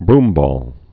(brmbôl, brm-)